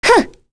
Lewsia_A-Vox_Attack5.wav